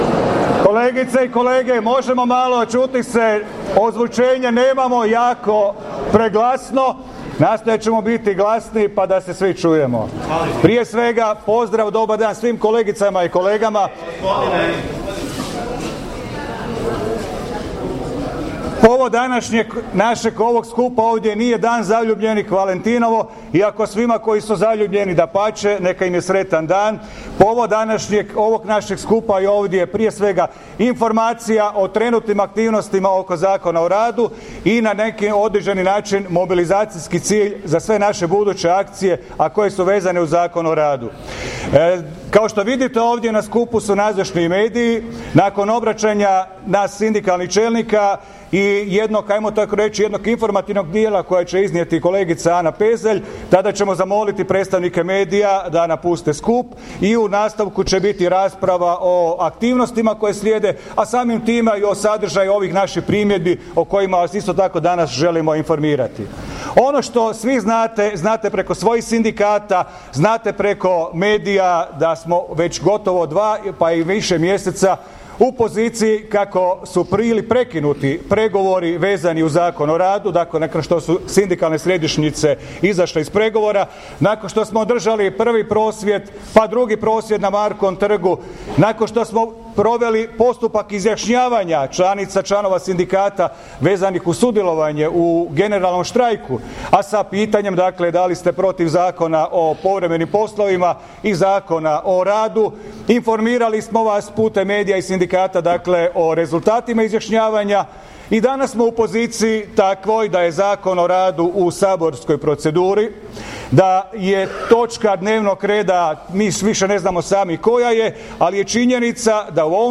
U prepunoj zagrebačkoj Tvornici kulture održan je prvi od četiri sastanka sindikalnih aktivista što ih u sklopu aktivnosti usmjerenih protiv donošenja Prijedloga novoga Zakona o radu organiziraju sindikalne središnjice – Nezavisni hrvatski sindikati, Savez samostalnih sindikata Hrvatske, Matica hrvatskih sindikata i Udruga radničkih sindikata Hrvatske.